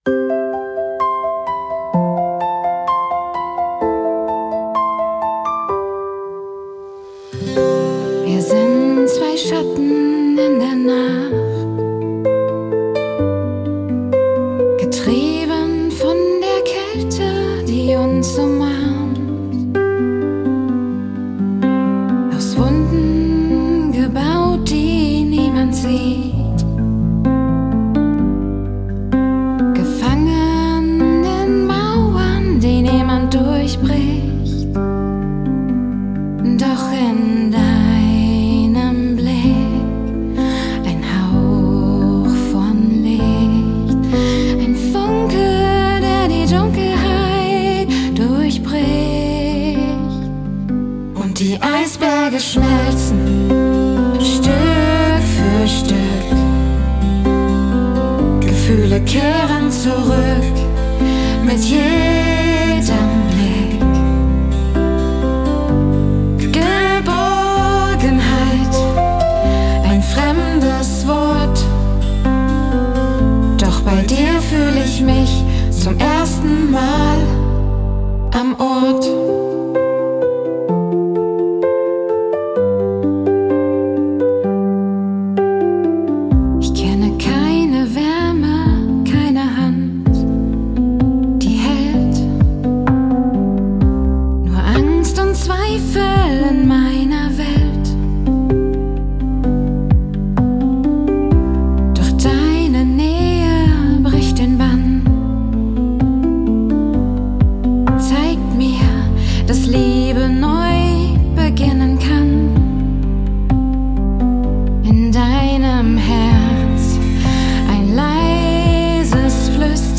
KI-Einzelaufnahme:
Pianoballade / Liebeslied.